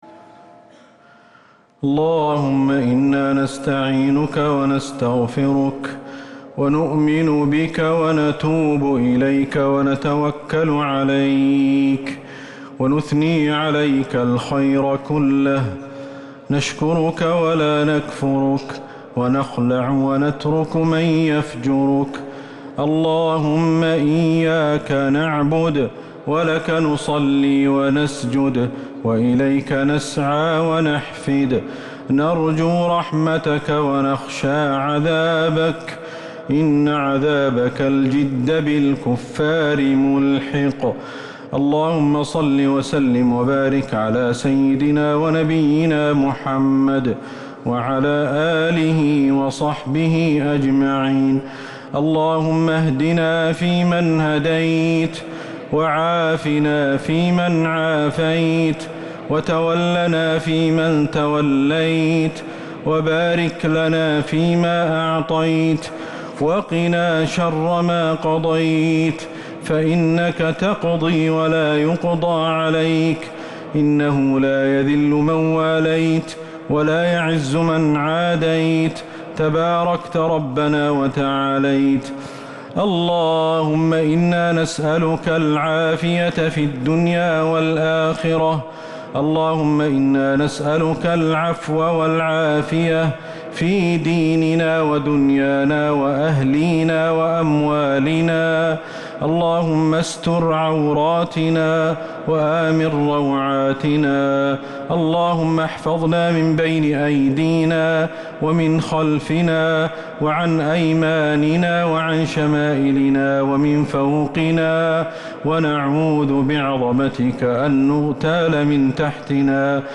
دعاء القنوت ليلة 7 رمضان 1444هـ | Dua 7 st night Ramadan 1444H > تراويح الحرم النبوي عام 1444 🕌 > التراويح - تلاوات الحرمين